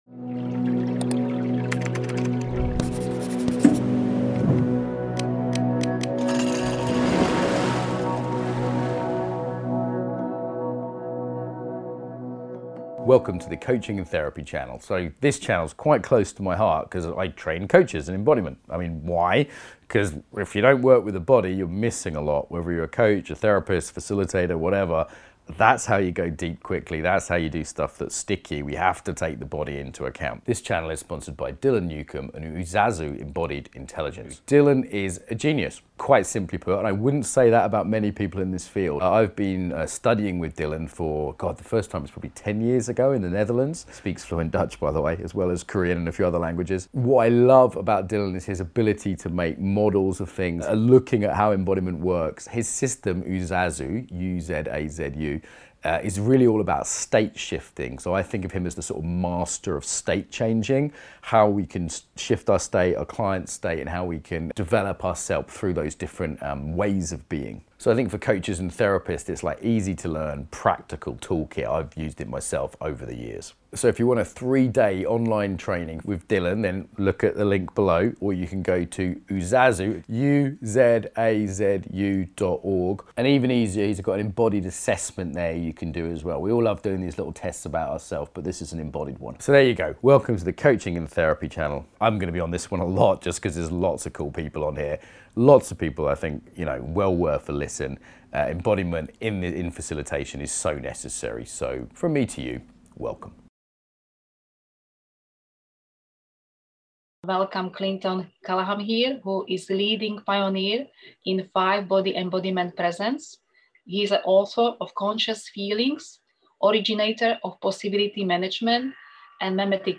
This is a hands-on nuts-and-bolts workshop for healers and change agents.